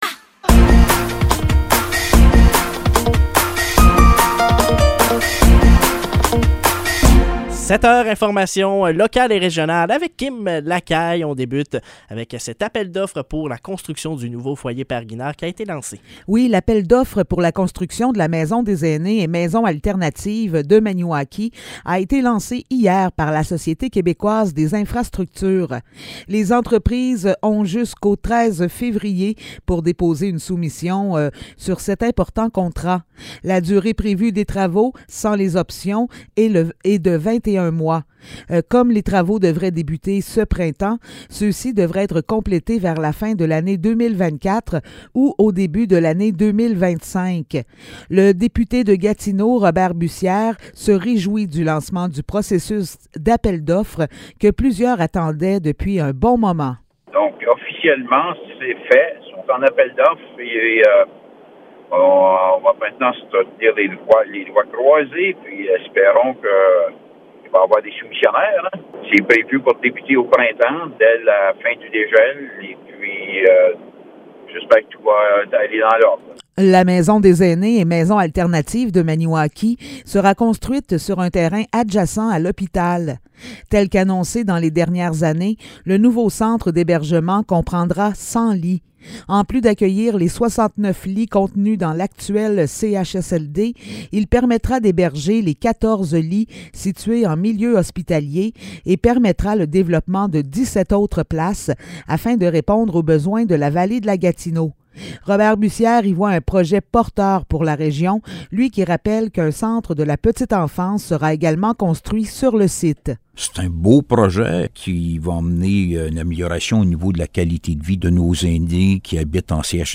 Nouvelles locales - 19 janvier 2023 - 7 h